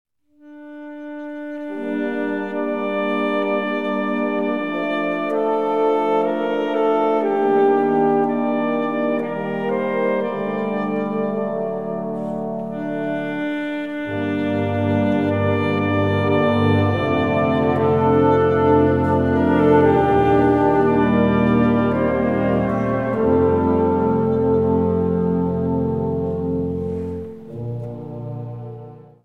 Unterkategorie Konzertmusik
Besetzung Ha (Blasorchester)